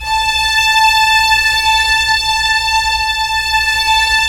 Index of /90_sSampleCDs/Roland L-CD702/VOL-1/STR_Vlns 1 Symph/STR_Vls1 Sym wh%